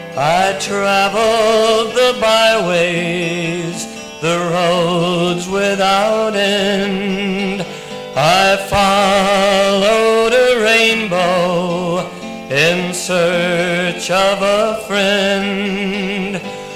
Demos recorded
in his living room on a Uher tape recorder.